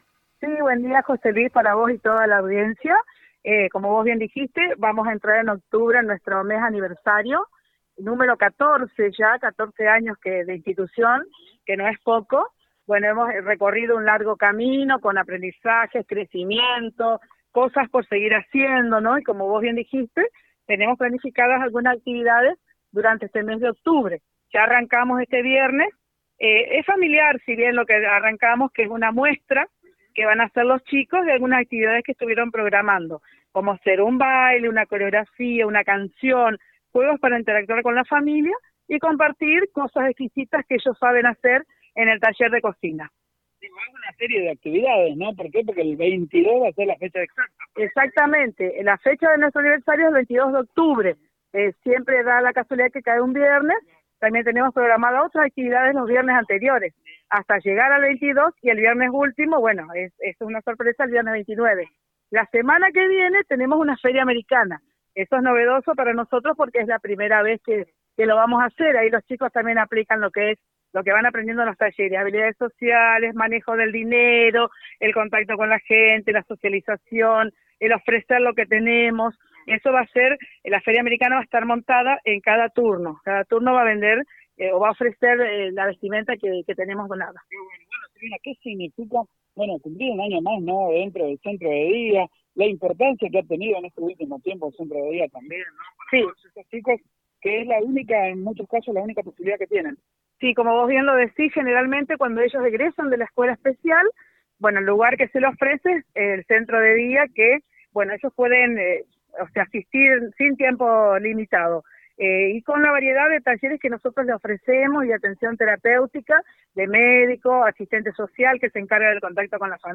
Escucha el Móvil en vivo desde el centro de día